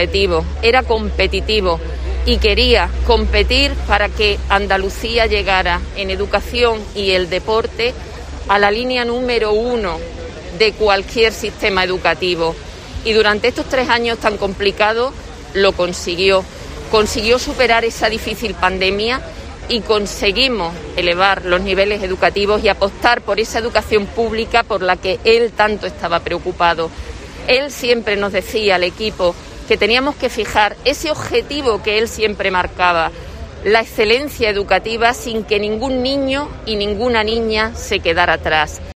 La Delegada de Educación en Granada Ana Berrocal recuerda emocionada al consejero Imbroda